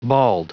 Prononciation du mot bald en anglais (fichier audio)
Prononciation du mot : bald